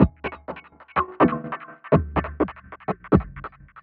tx_perc_125_dubbells1.wav